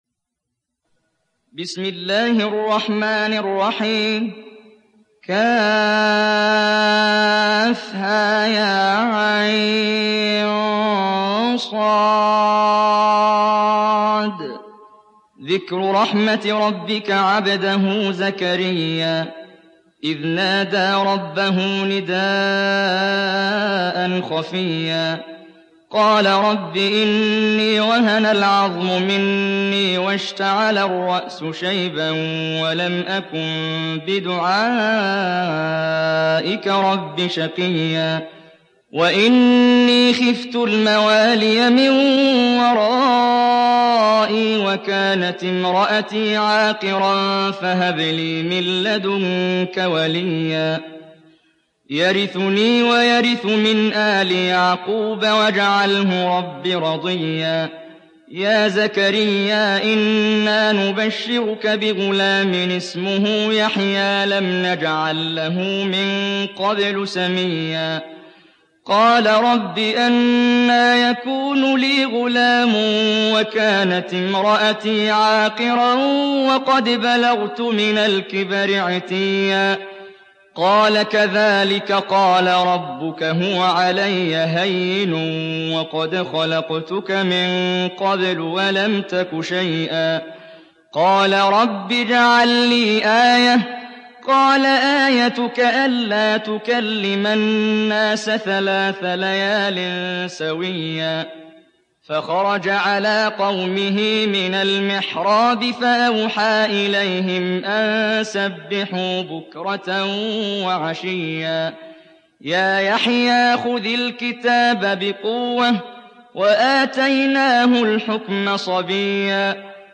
تحميل سورة مريم mp3 بصوت محمد جبريل برواية حفص عن عاصم, تحميل استماع القرآن الكريم على الجوال mp3 كاملا بروابط مباشرة وسريعة